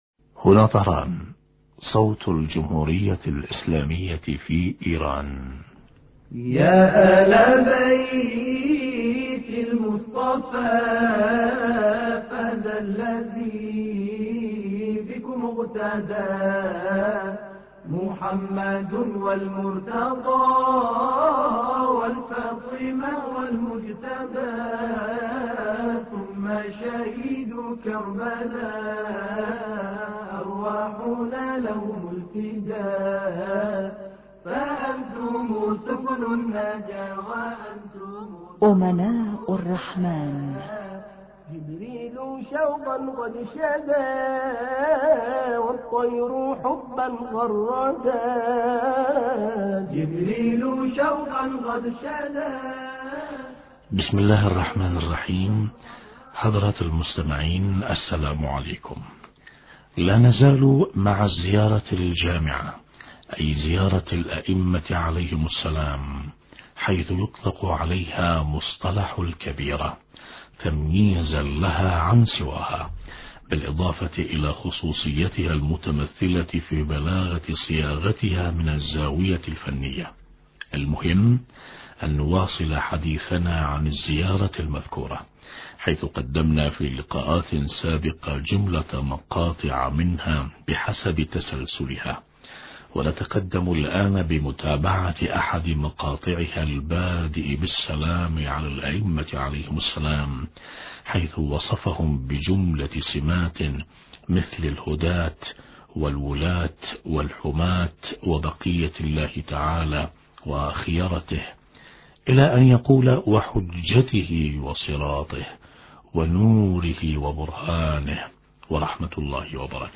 أما الآن نتابع تقديم برنامج امناء الرحمن بهذا الاتصال الهاتفي